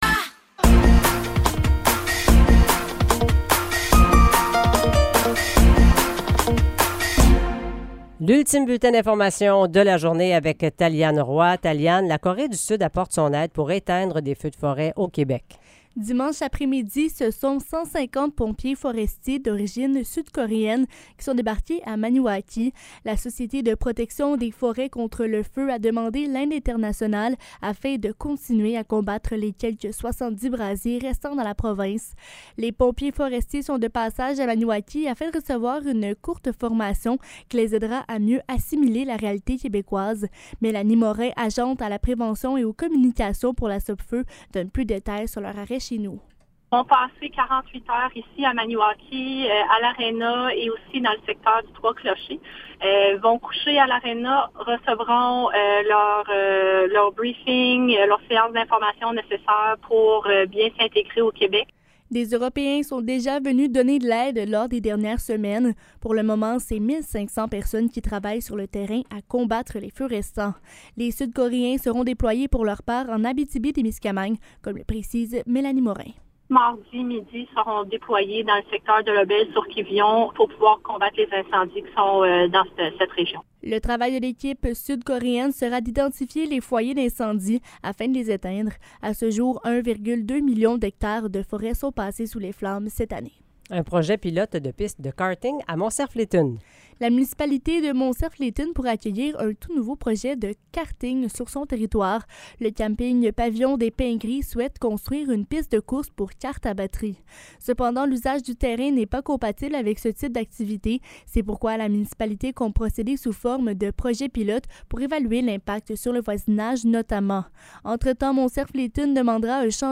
Nouvelles locales - 3 juillet 2023 - 17 h